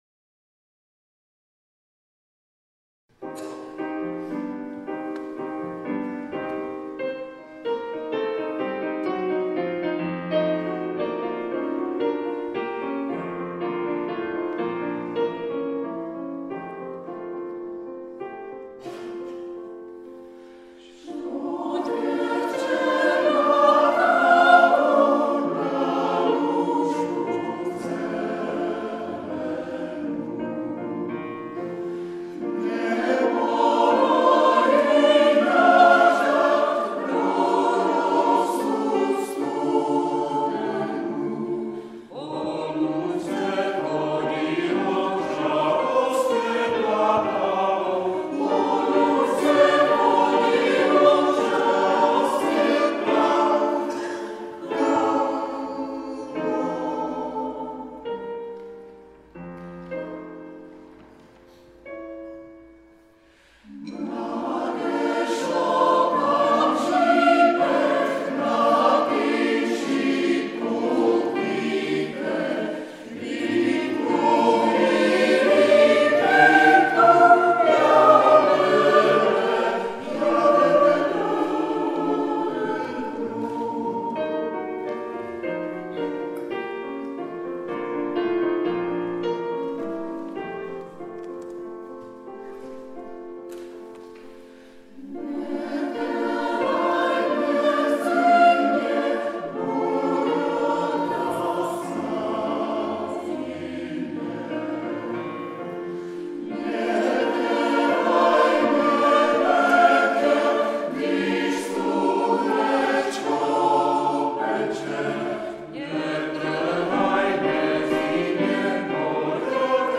Zvuková ukázka z vystoupení v kostele Panny Marie v Železné Rudě